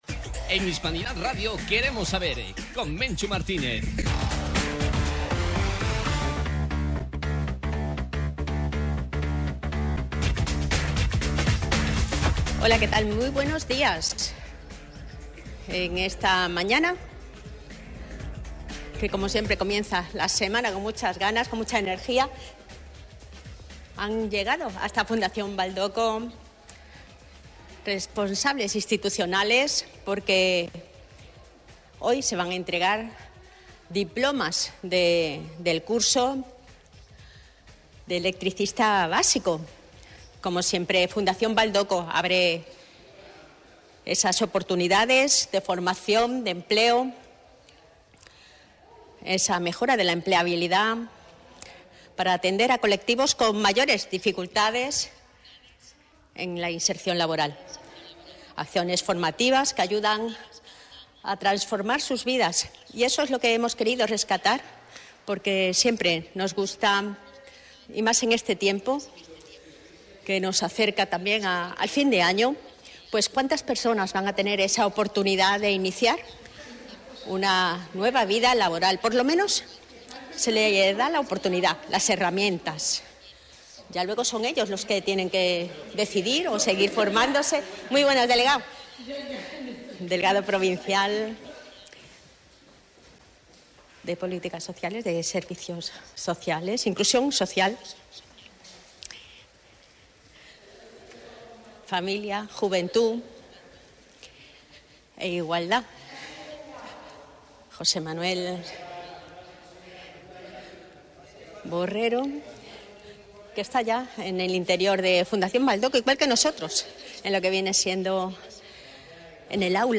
Estuvimos en directo desde Fundación Valdocco de Huelva en la entrega a su alumnado de los Certificados de la realización del Curso Básico de Electricidad, gracias al Puerto de Huelva. Una acción formativa que acredita y ayuda a la mejora de empleabilidad y oportunidades en este sector tan demandado.